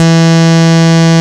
73.02 BASS.wav